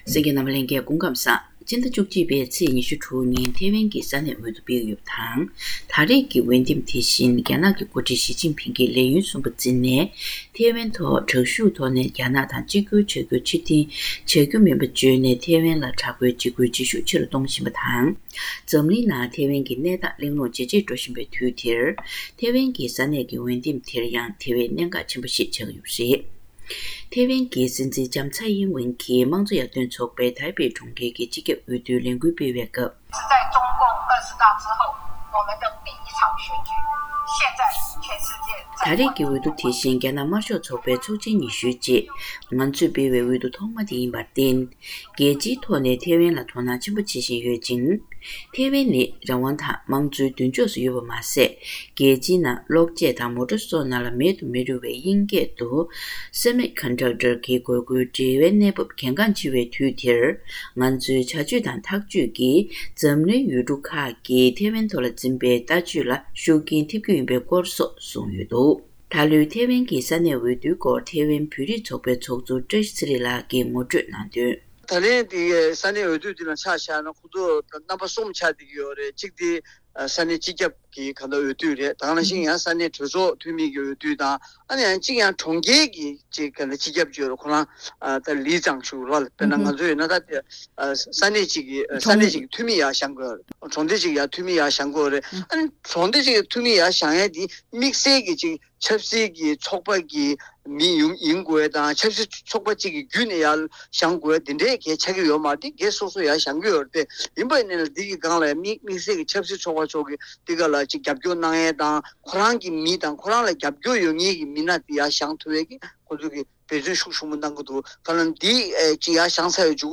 བཀའ་འདྲི་ཕྱོགས་སྒྲིག